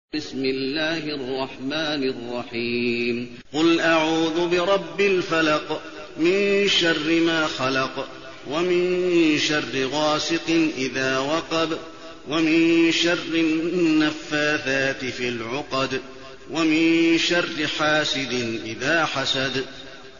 المكان: المسجد النبوي الفلق